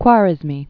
(kwärĭz-mē, wär-), al- Full name Muhammad ibn-Musa al-Khwarizmi. 780?-850?